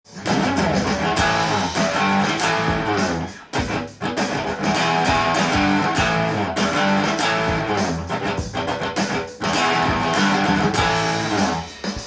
６弦の５フレットパワーコードや
このパワーコード達を使いリフを作り　ロックな